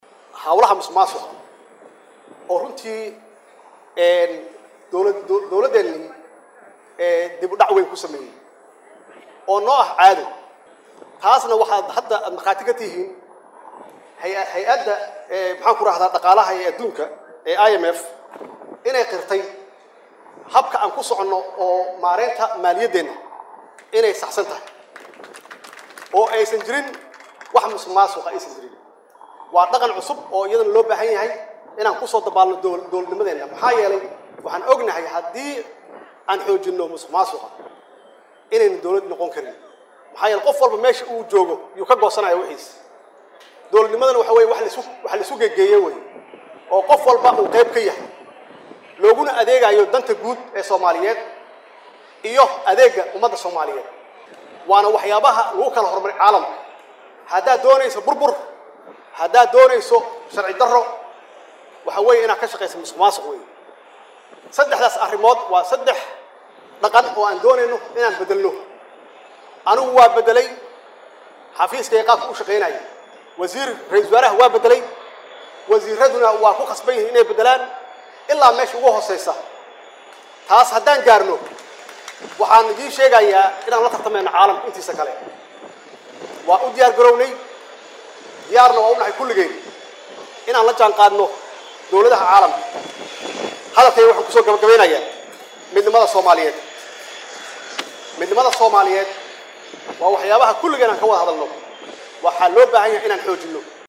Hadalkaan ayuu ka sheegay Madaxweynaha Jamhuuriyadda Soomaaliya mudane Maxamed C/laahi xili shalay uu ka qeyb galay Munaasabadii xariga looga jarayay Maamulka Hawada Soomaaliyeed.